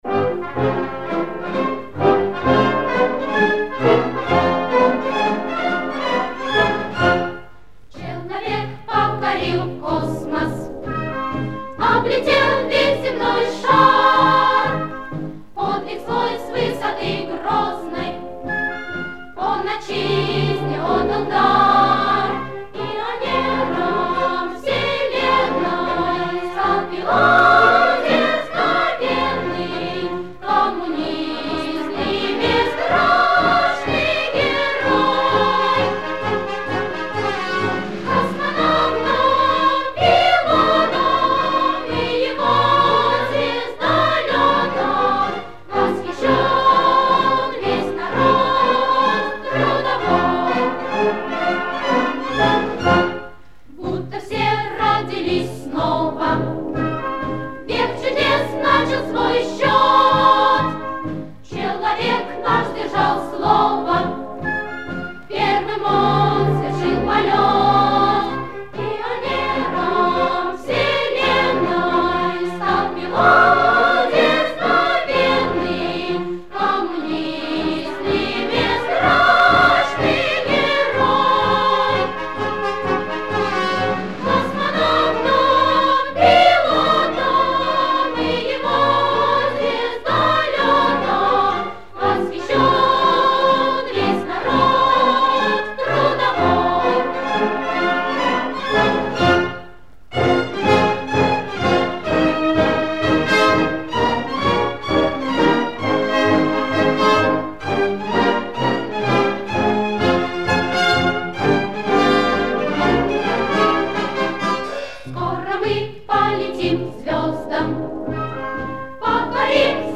Источник собственная оцифровка